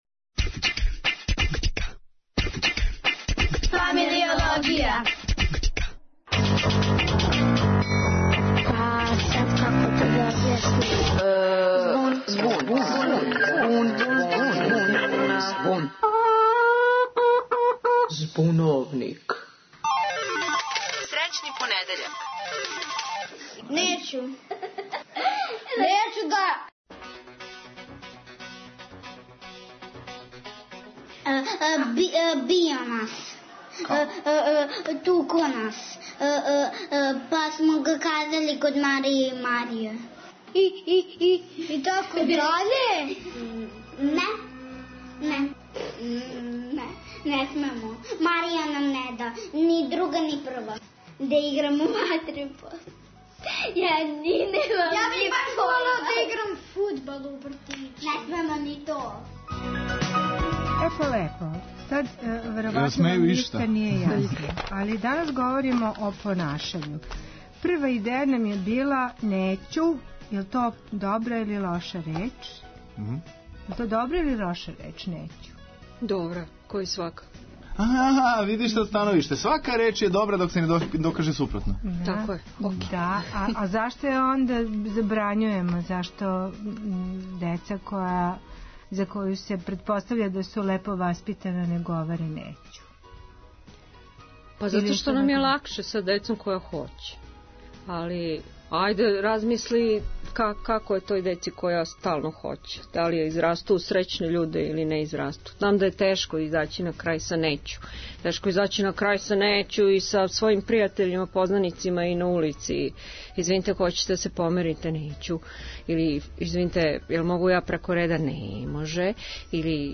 Шта бисте ви додали?Можда одузели?У Збуновнику говоре деца, млади и психолог